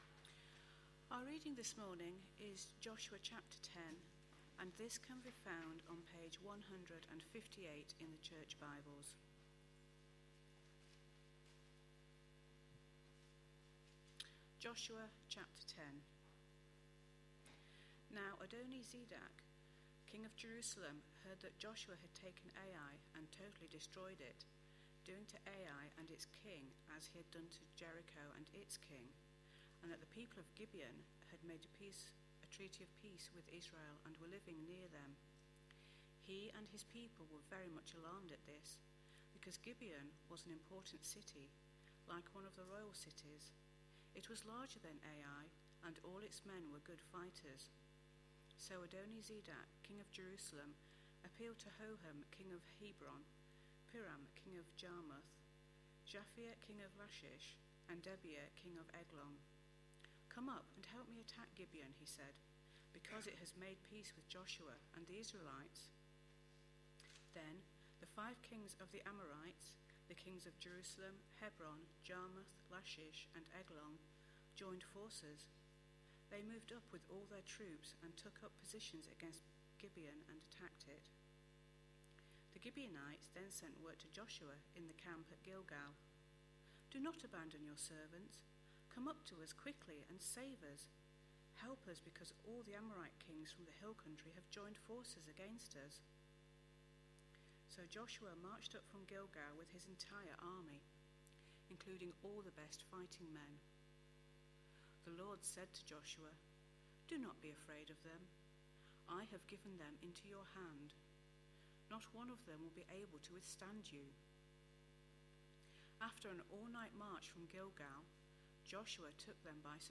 A sermon preached on 17th June, 2012, as part of our Entering God's Rest series.